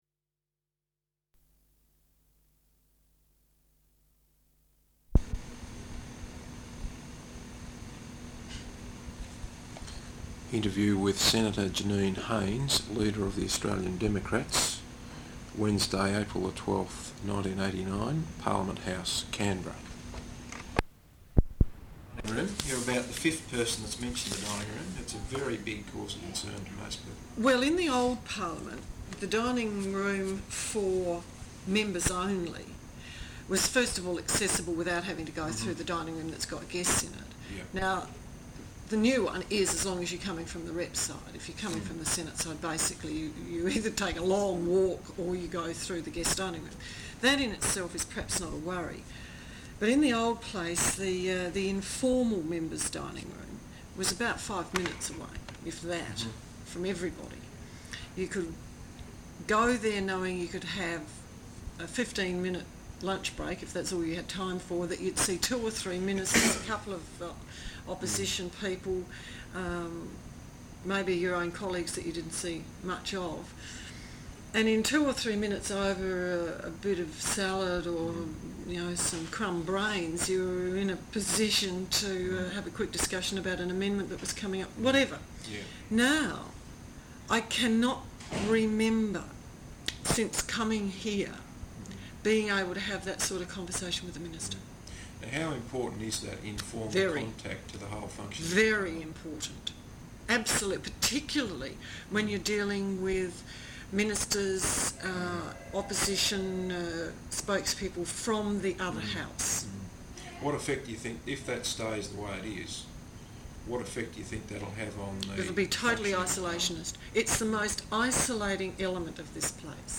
Interview with Senator Janine Haines, Leader of the Australian Democrats, Wednesday April 12th, 1989, Parliament House, Canberra.